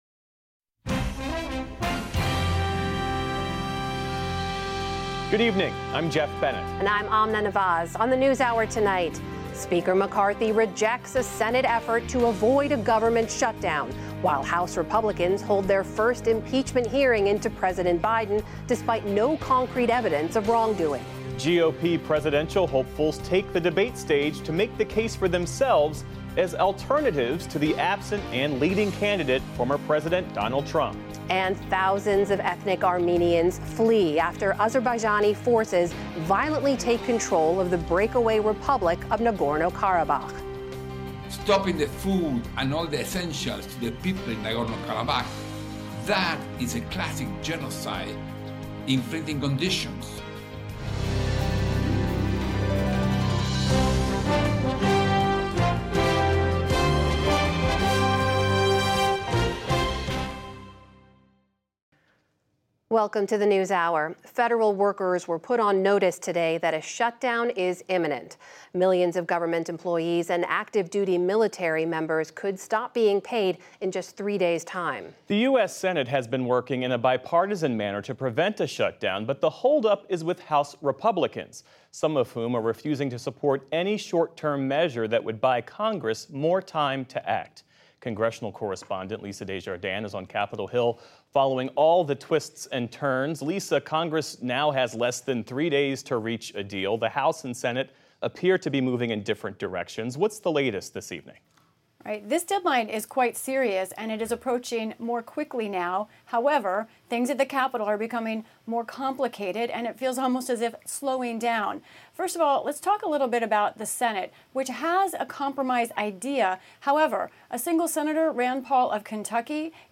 Published each night by 9 p.m., our full show includes every news segment, every interview, and every bit of analysis as our television broadcast.